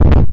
sf3 Jswing游戏SF X " 秋天R
描述：下降（右键）